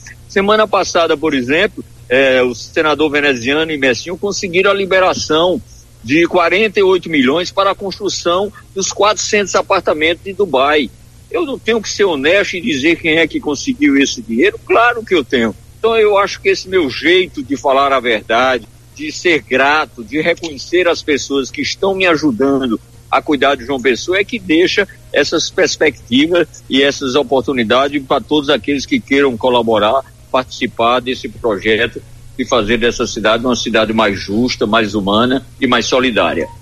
O agradecimento foi durante entrevista à imprensa pessoense.